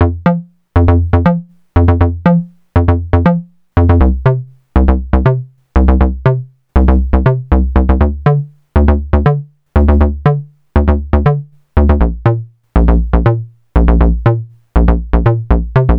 TSNRG2 Bassline 025.wav